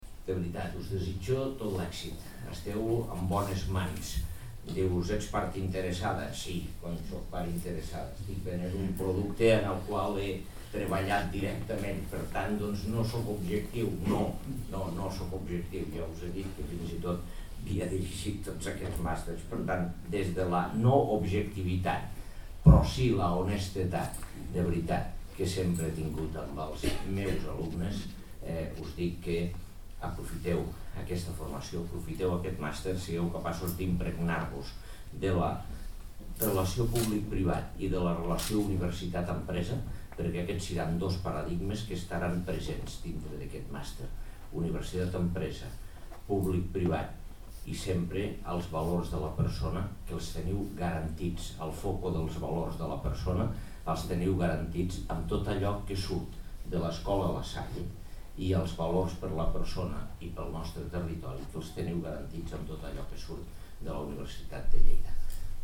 Tall de veu de l'alcalde, Àngel Ros, sobre el Màster en Direcció d'Empreses Executive MBA de la UdL-La Salle (1.0 MB) Fotografia 1 amb major resolució (1.9 MB) Fotografia 2 amb major resolució (1.6 MB) Díptic International MBA (342.7 KB)
tall-de-veu-de-lalcalde-angel-ros-sobre-el-master-en-direccio-dempreses-executive-mba-de-la-udl-la-salle